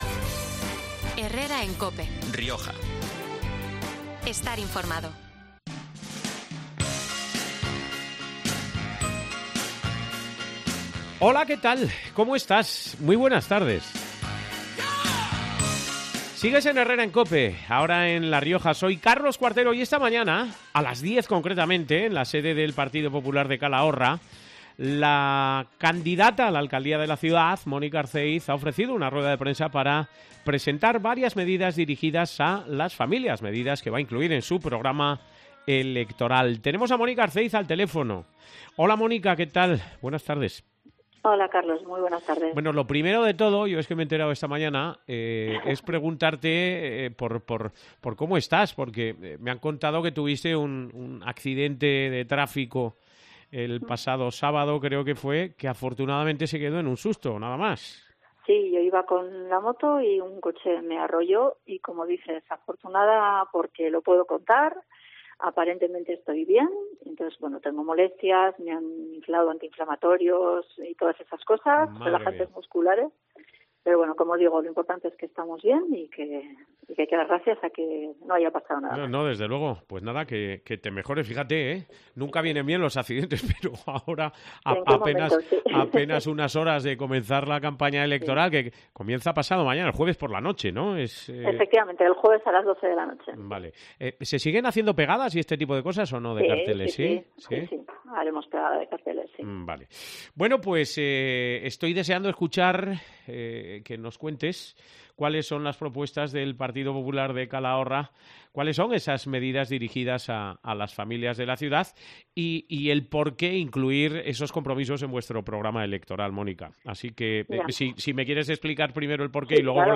en los micrófonos de COPE Rioja. La nueva Concejalía de Familia estará orientada a prestar atención, ayudas, asesoramiento y ofrecer soluciones para la conciliación familiar de mayores y pequeños.